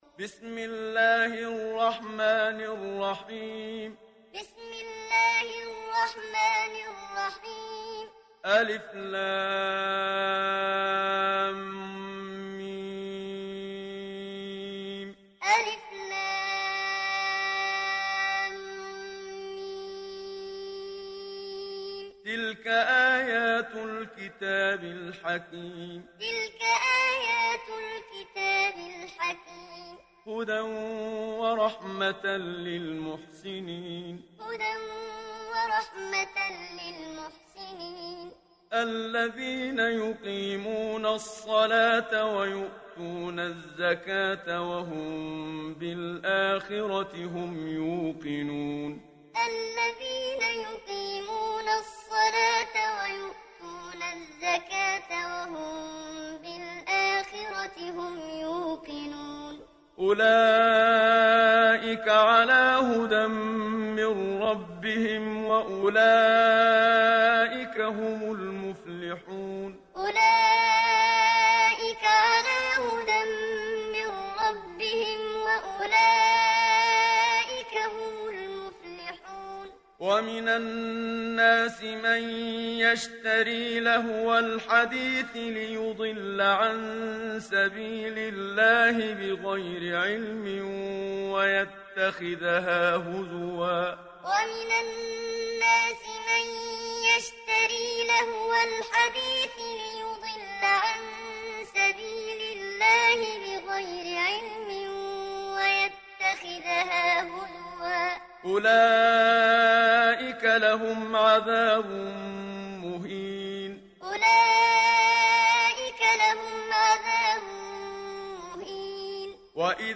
دانلود سوره لقمان mp3 محمد صديق المنشاوي معلم (روایت حفص)